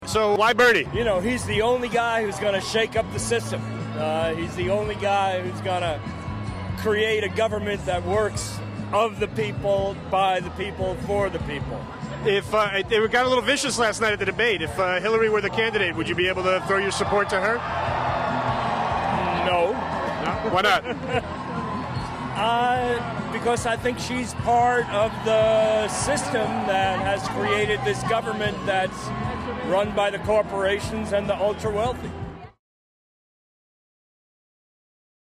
Ben Cohen tells Reason TV Clinton is "part of the system."
Reason TV caught up with Cohen at the McIntyre-Shaheen 100 Club Celebration in Manchester, NH, where he told us if Sanders falls short of winning the Democratic nomination for president, he won't vote for Hillary because she's "part of the system that has created this government run by the corporations and the ultra-wealthy."